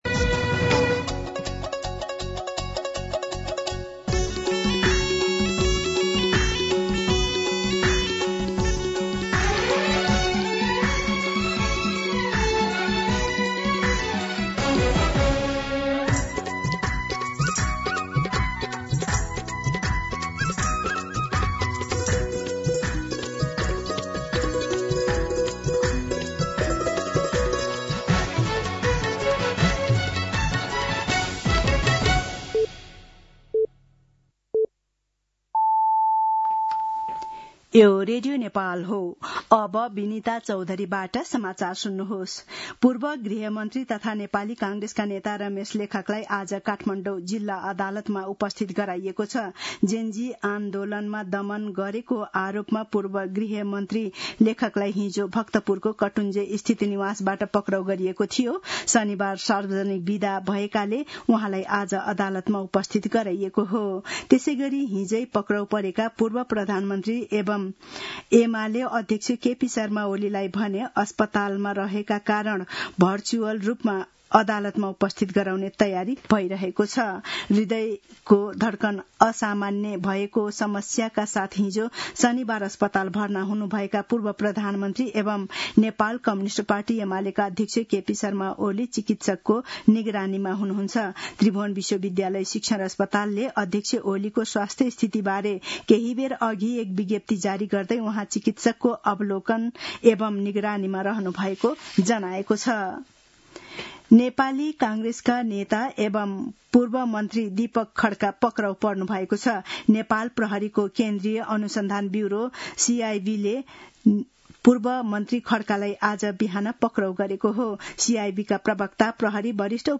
दिउँसो ४ बजेको नेपाली समाचार : १५ चैत , २०८२